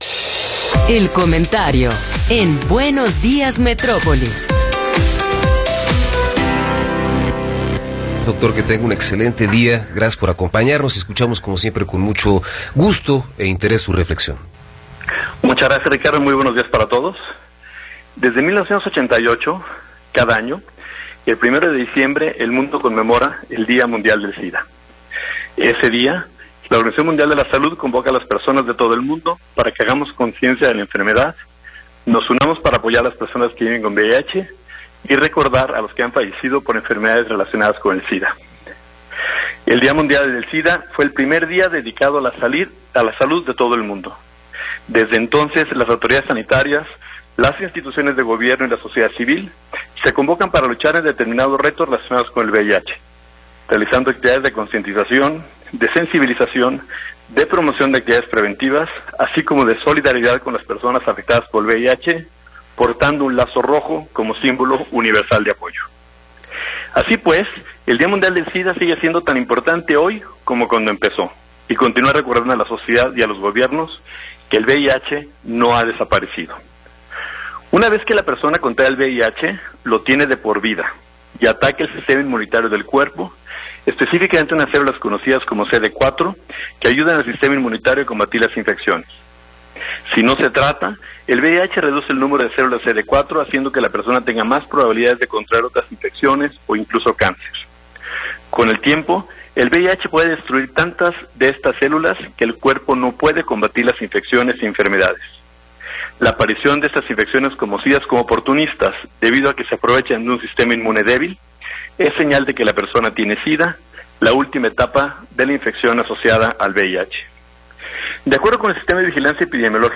Comentario de Alfonso Petersen Farah – 7 de Diciembre de 2022